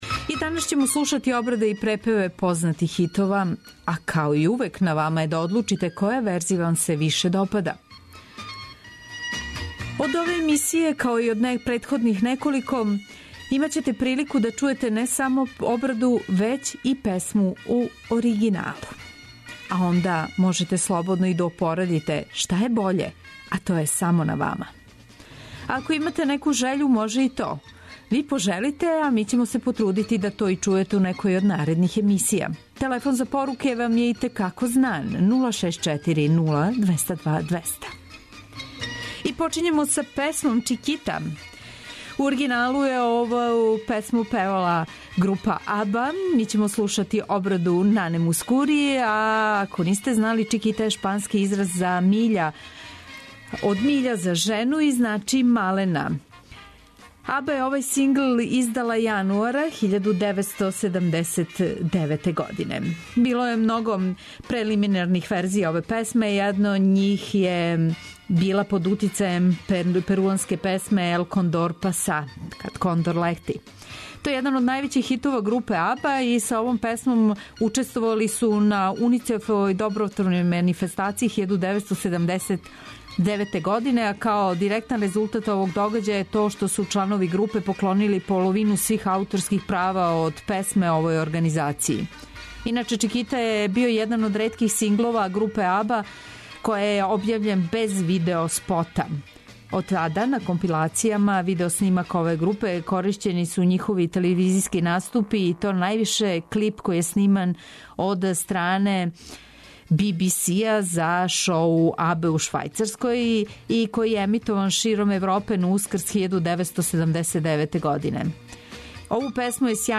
преузми : 56.35 MB Имитација Autor: Београд 202 Имитација је емисија у којој се емитују обраде познатих хитова домаће и иностране музике.